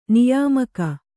♪ niyāmaka